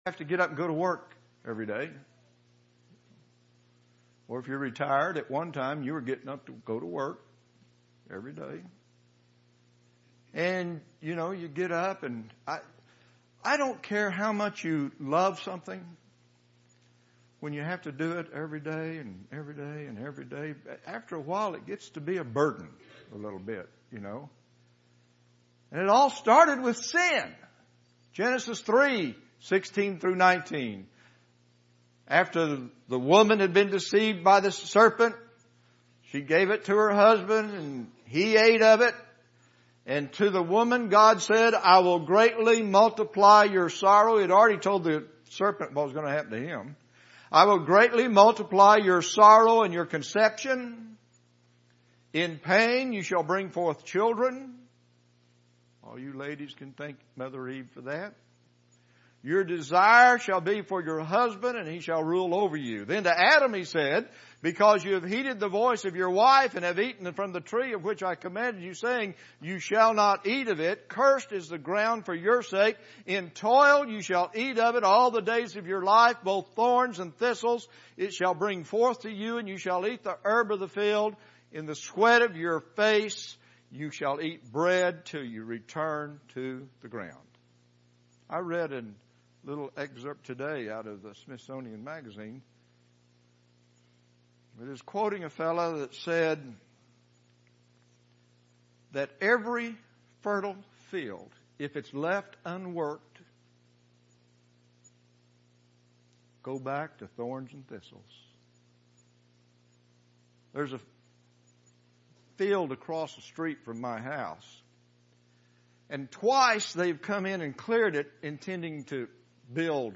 Gospel Meetings , Weekly Sermons ← Newer Sermon Older Sermon →